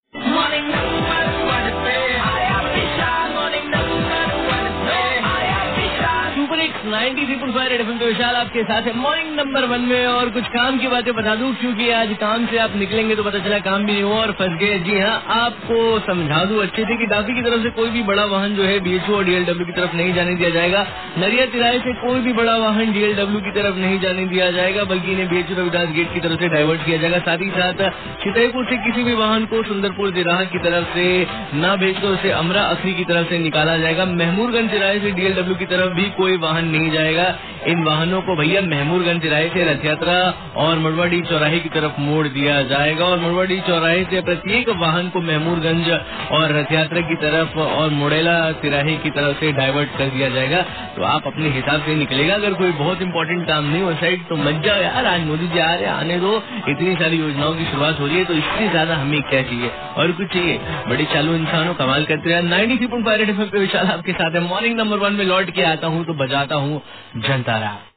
traffic update